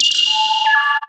get_bomb.wav